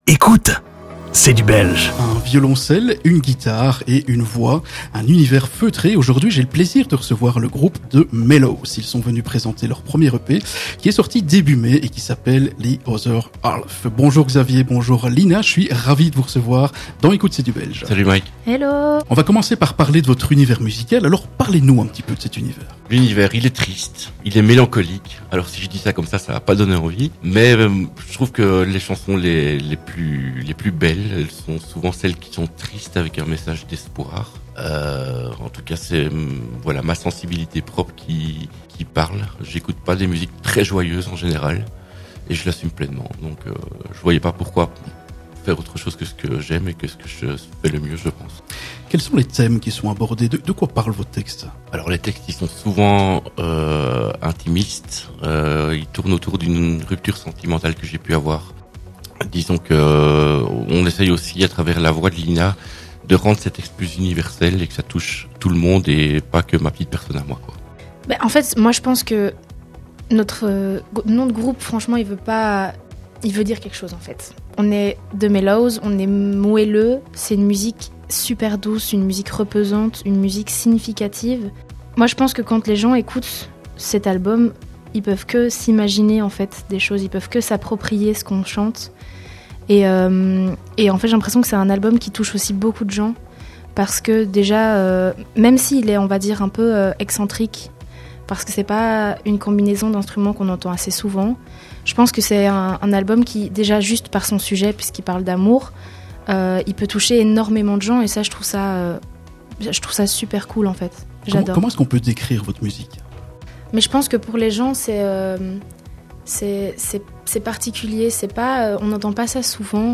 Rencontre et interview du groupe THE MELLOW'S
The Mellow's en session acoustique dans les studios d'Ultrason Prochains concerts 16.10.25 | Braine-le-Comte | La Verrerie Actualité Retrouvez toute l'actualité de The Mellow's sur Facebook et Instagram